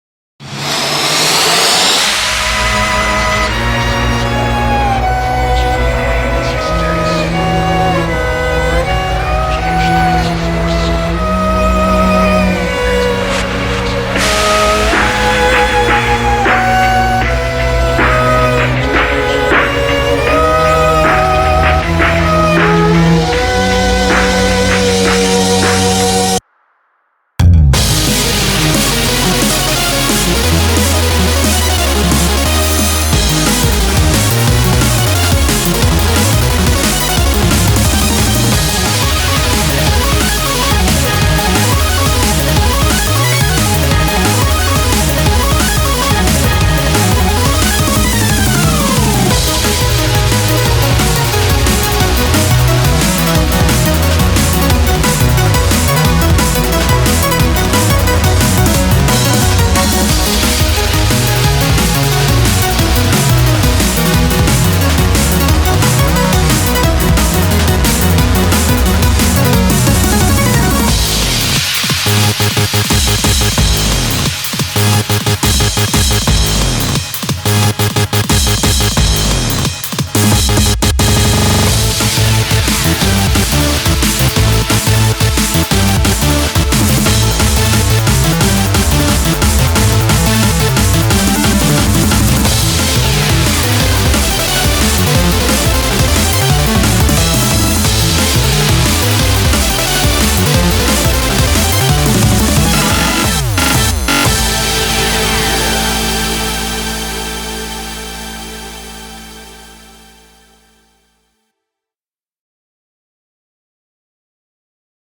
BPM79-178
Audio QualityPerfect (High Quality)
Comments[ELECTRIC FUSION]